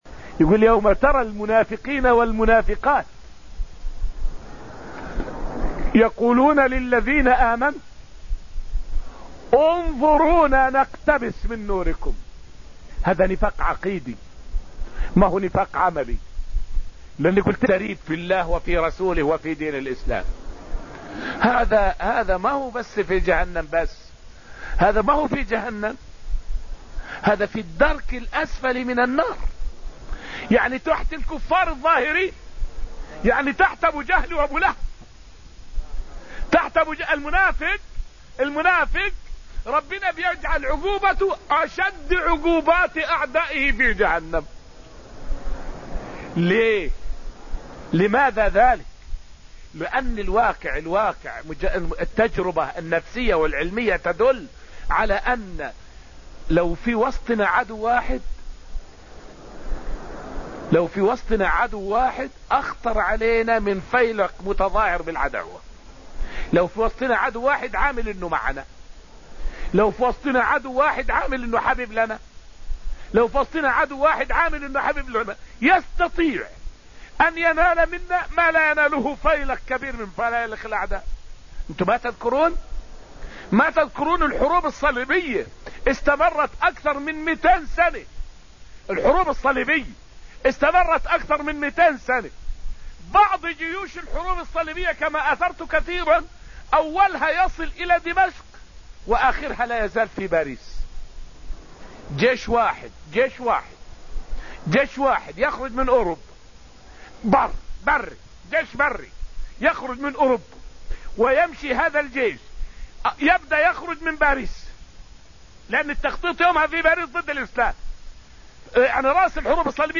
فائدة من الدرس الخامس عشر من دروس تفسير سورة الحديد والتي ألقيت في المسجد النبوي الشريف حول خطر المنافق على الأمة أشد من خطر العدو الظاهر.